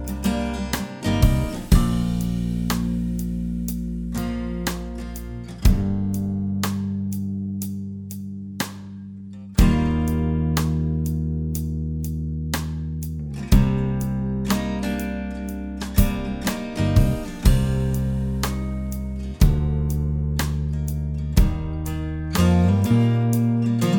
Swears Removed From Backing Vocals Rock 5:15 Buy £1.50